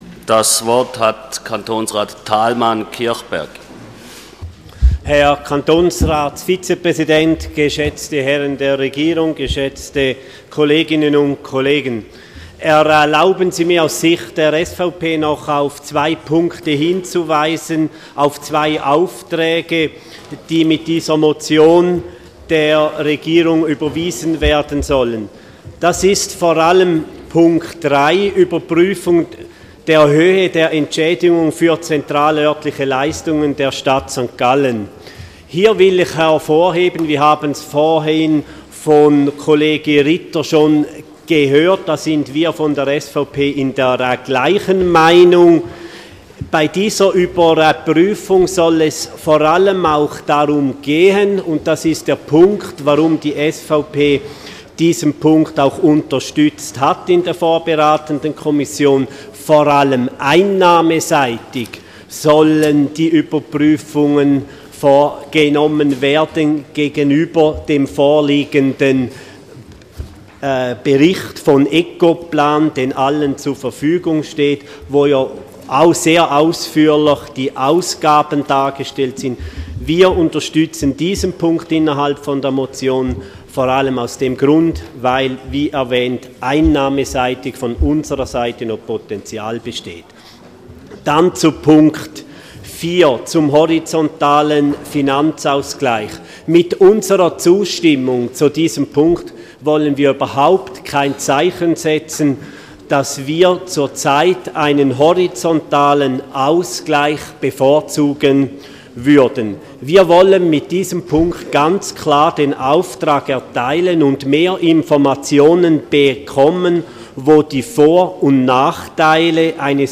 Kommissionspräsident: Die Motion ist gutzuheissen.
Session des Kantonsrates vom 24. und 25. September 2012